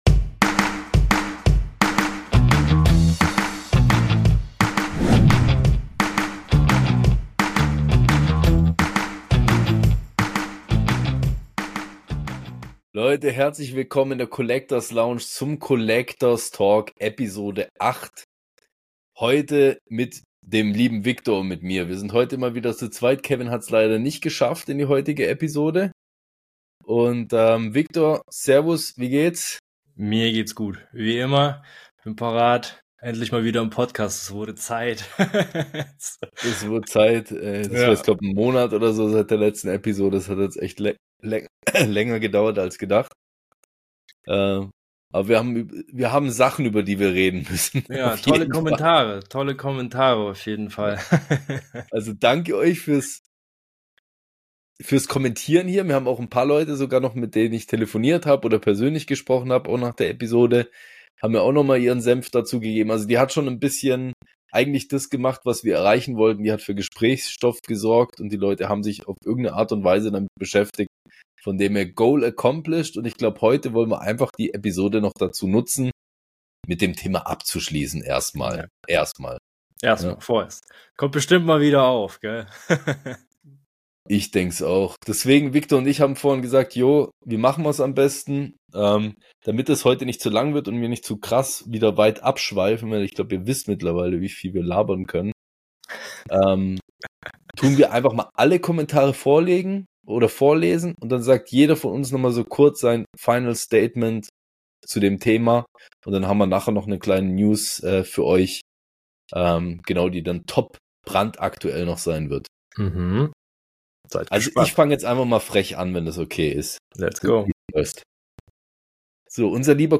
Ein entspannter Talk unter drei Sammler-Freunden!